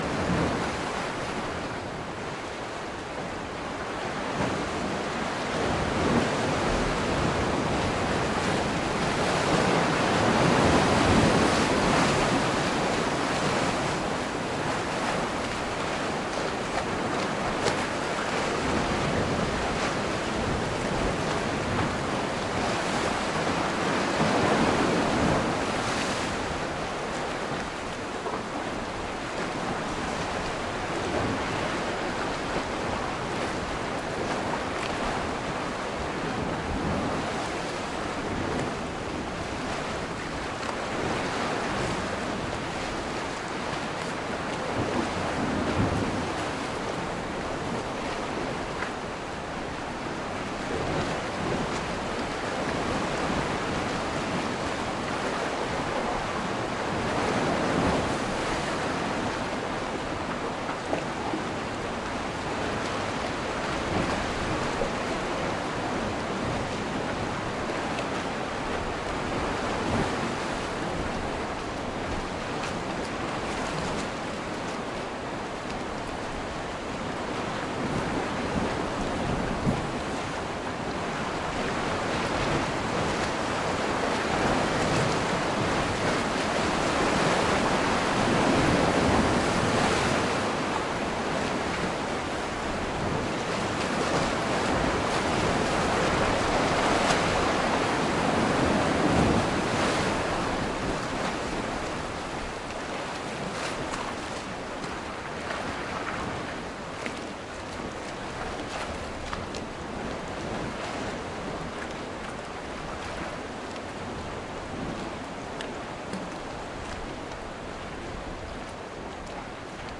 氛围 " 岩石岬
描述：对岩石岬角上的一个海湾进行立体声现场录音。Zoom H2后置机载话筒。
Tag: 场记录 岬角 岩S 飞溅 立体声 波浪 XY